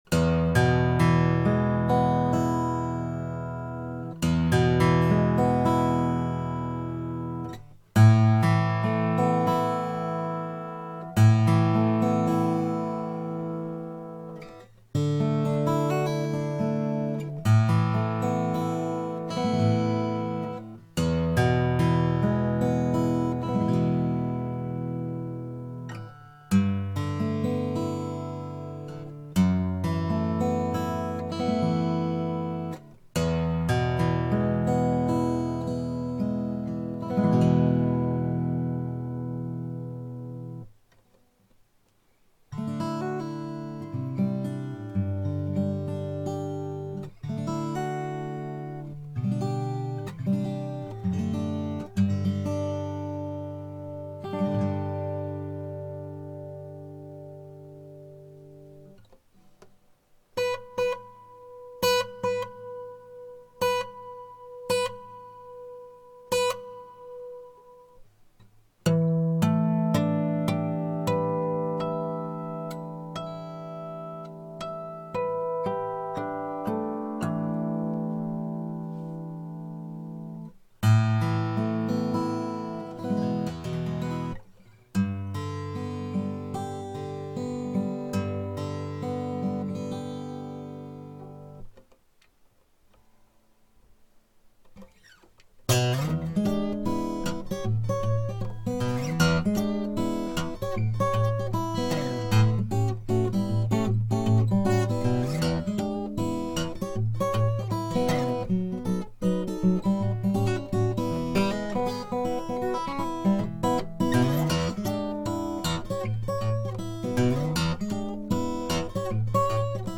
生音が聞けるようにしてみました。
メロディーがきれいにでて，倍音にはビンテージのようなコーラス感が最初から存在しています。 アルペジオの音が軽くゆれている感じがとても癒されます。
今回も録音はiPodです。 リバーブ感やコーラス感がわかるでしょうか。 L-00 新岡ギター教室モデル コンター加工入りの音 完成したばかりのギターでこの音が出るというのがすごいです。